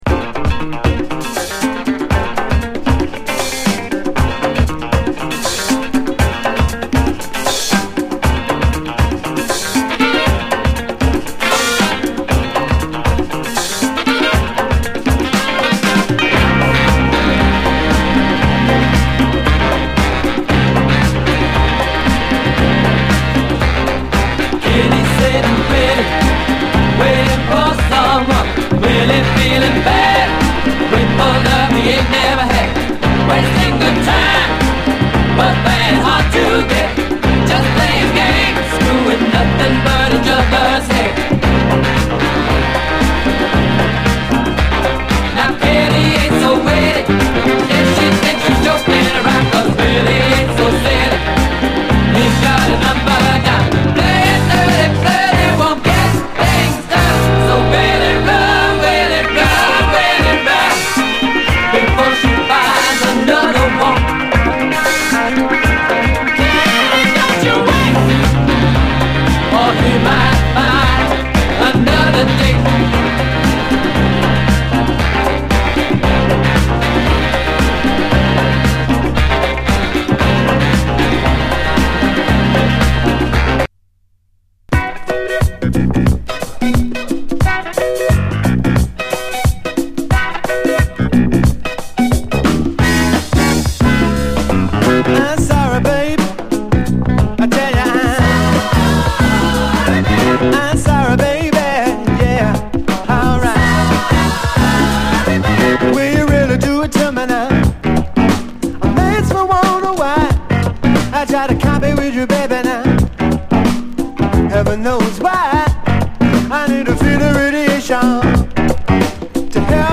甘いソプラノ・サックスから一気に引き込まれるプリAOR